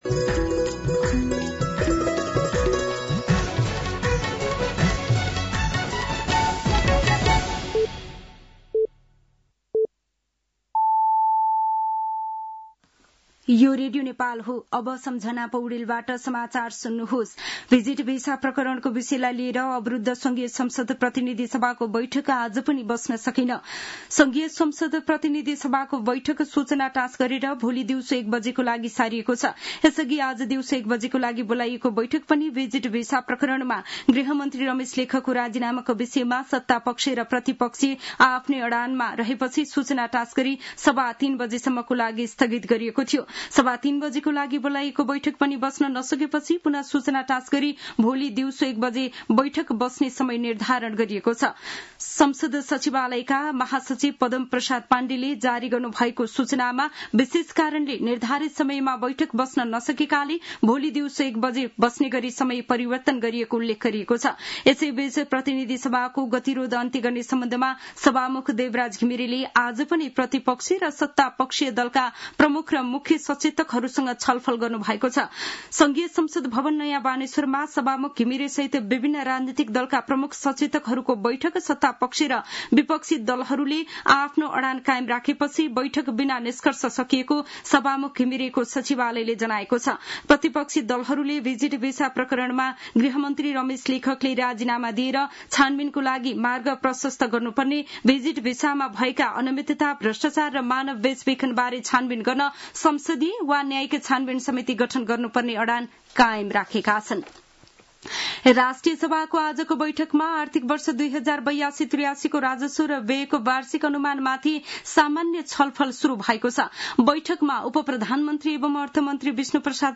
साँझ ५ बजेको नेपाली समाचार : २१ जेठ , २०८२